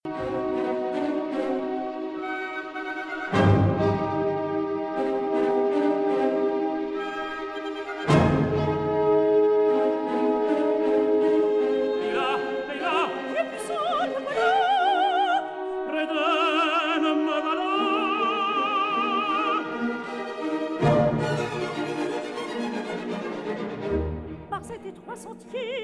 soprano
tenor
baritone
bass